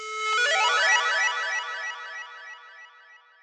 sparkle.wav